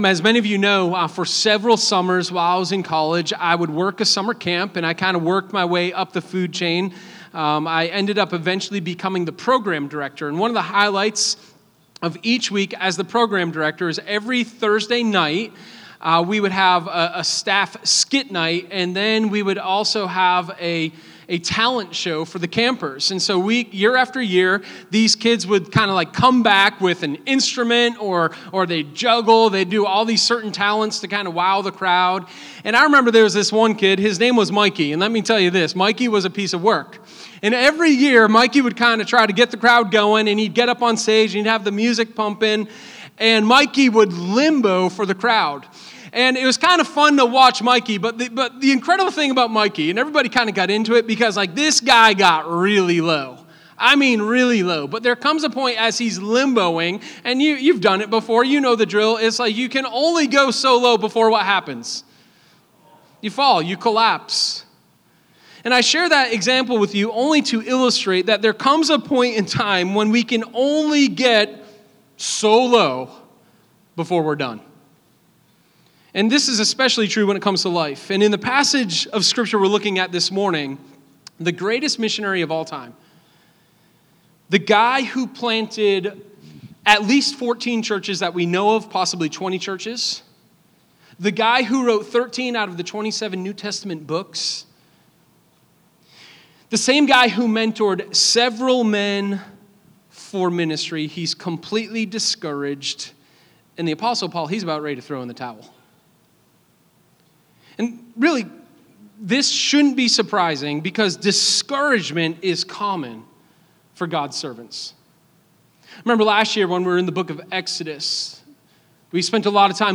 Sermon1110_Surrender-your-Discouragement.m4a